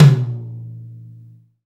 TOM XTOMMI0A.wav